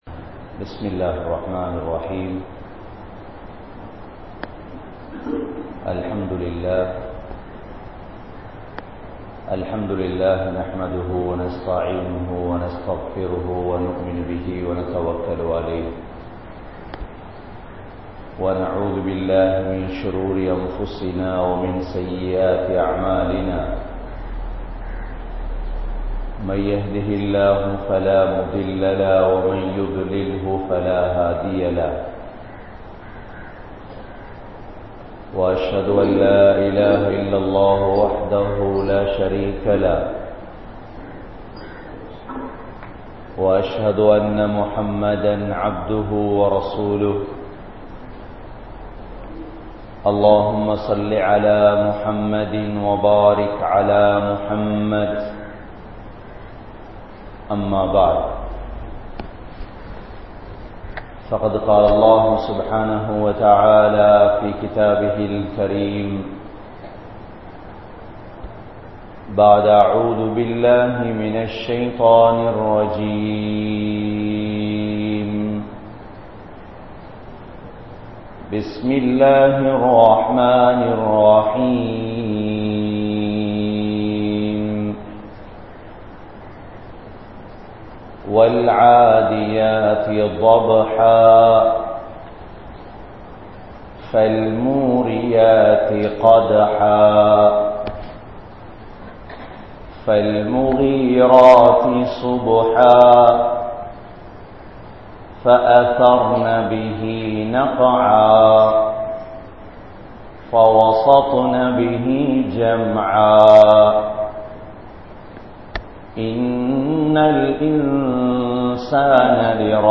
Padaithavanai Marantha Samooham(படைத்தவனை மறந்த சமூகம்) | Audio Bayans | All Ceylon Muslim Youth Community | Addalaichenai
Oluvil 05, Ansari Jumua Masjith